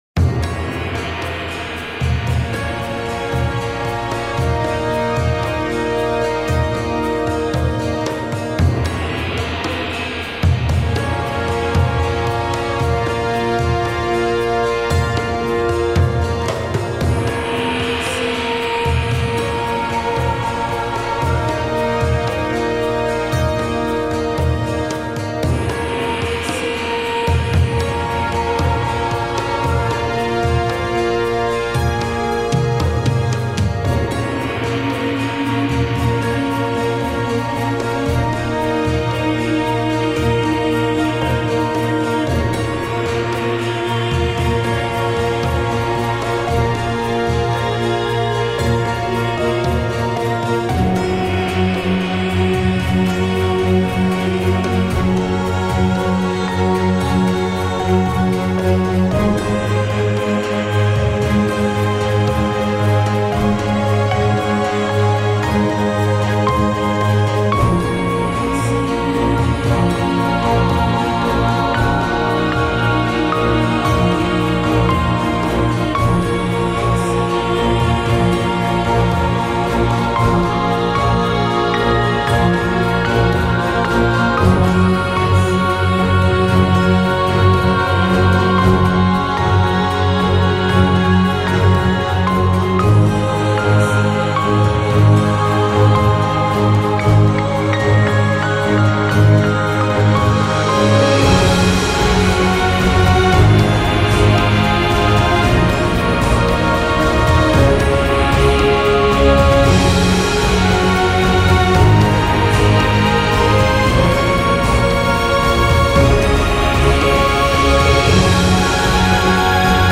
ジャンルクラシック
BPM１１４
使用楽器ピアノ、ストリングス、ボイス、合唱団
解説フルオーケストラと合唱によるフリーBGMです。
世界大戦後の文明が崩壊した時代をテーマに、哀愁・孤独・希望を美しい歌声で表現しました。
感動的、神秘的なコンテンツ、もの悲しい街や村のBGM、切ないオープニングシーンなどにお使いください。
オーケストラ/室内楽(Orchestra/chamber)